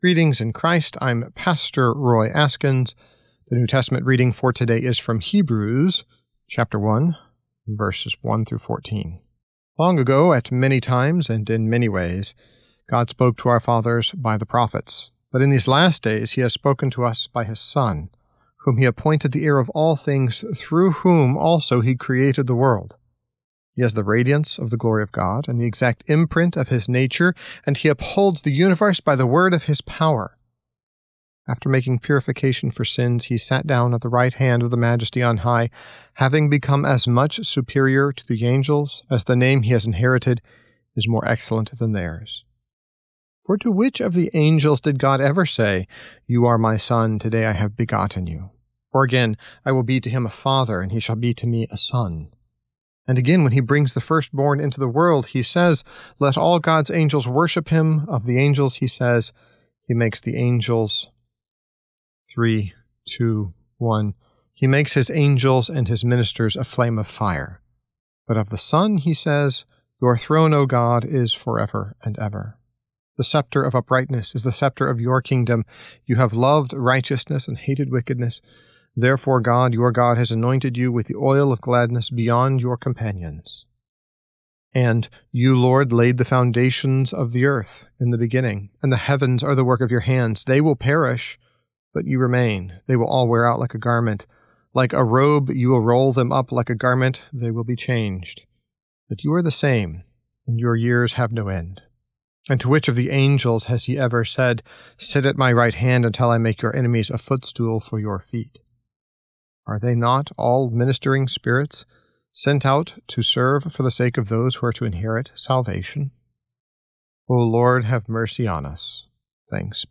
Morning Prayer Sermonette: Hebrews 1:1-14
Hear a guest pastor give a short sermonette based on the day’s Daily Lectionary New Testament text during Morning and Evening Prayer.